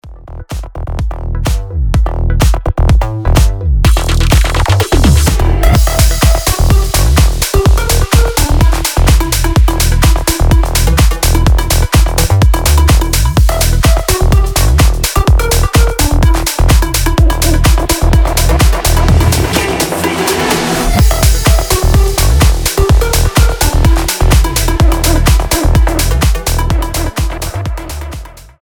клубные , electro house
динамичные